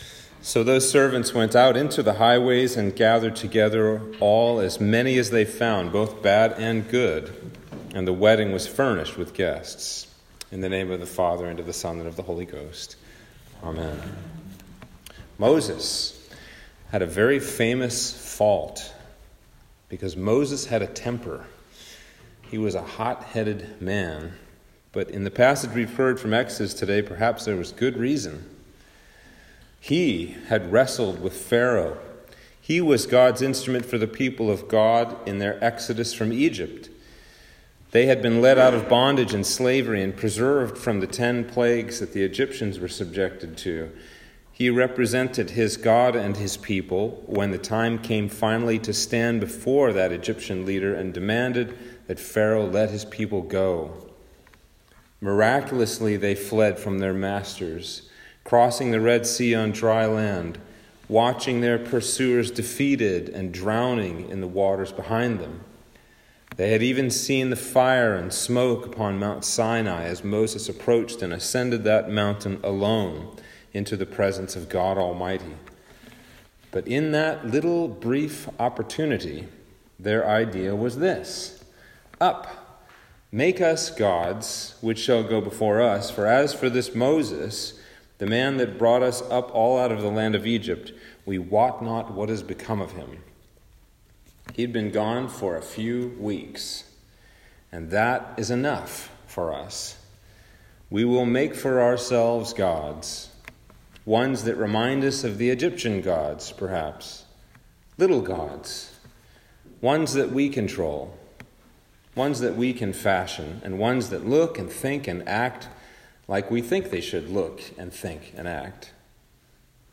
Sermon for Trinity 20 - 2021